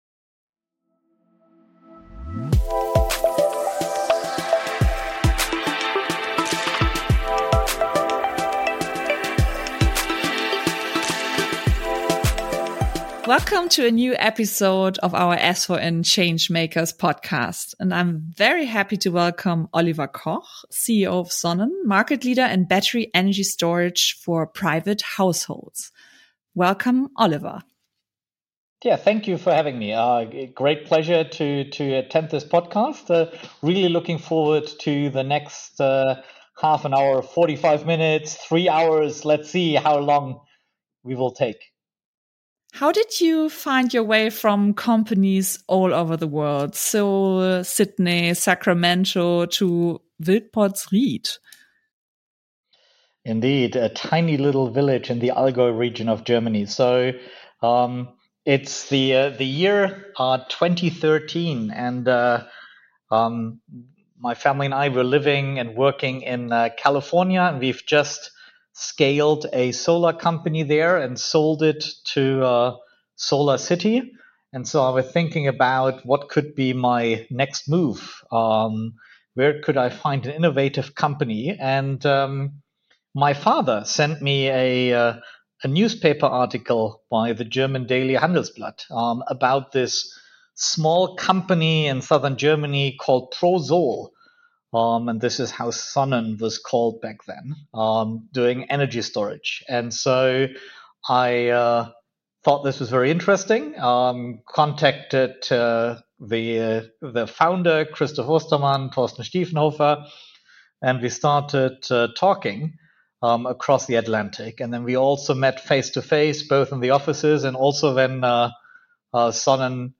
From California to the Allgäu – in our latest ESFORIN Change Makers Podcast, we interview